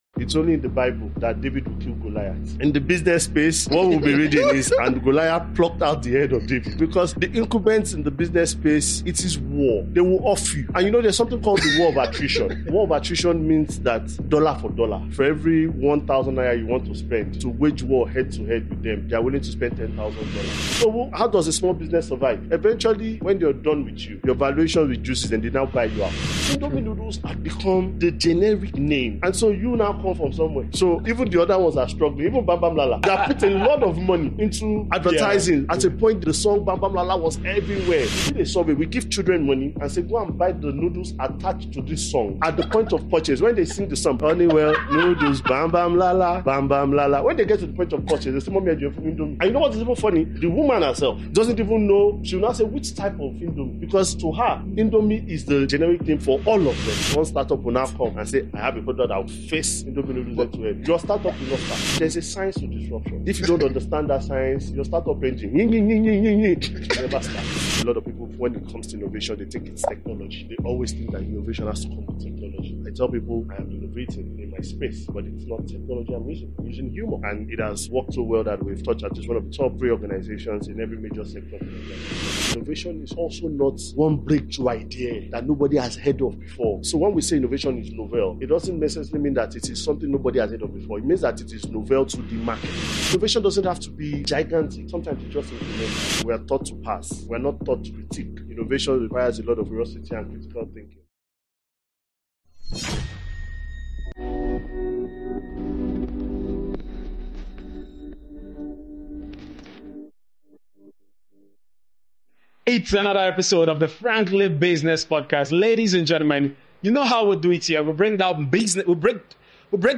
The Frankly Business Podcast is a platform for clear, intelligent conversations on business, finance, and the economy across Nigeria and Africa.